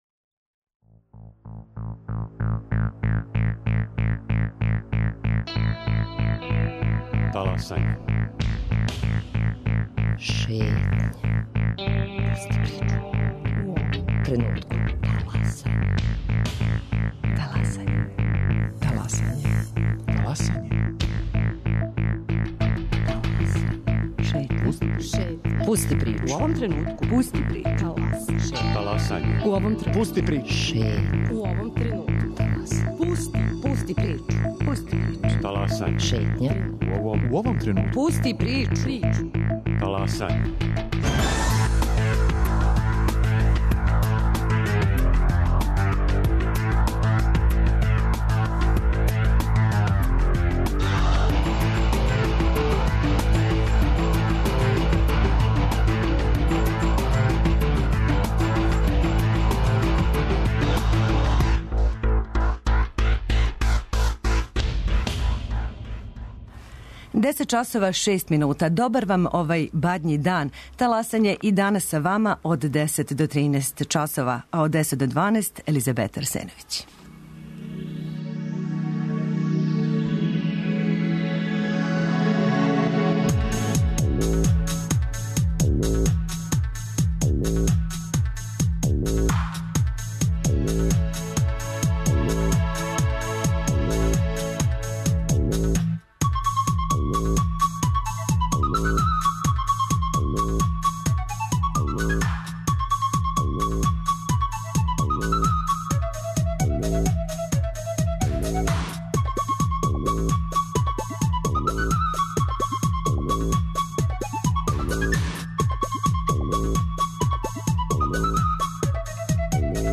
Данас, на Бадњи дан, водимо вас у Вукманово, село у подножју планине Селичевице, недалеко од Ниша.
Радио Београд 1, 10.05 На насловној фотографији планина Селичевица.